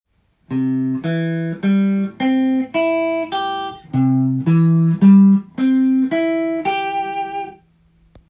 Cコードトーン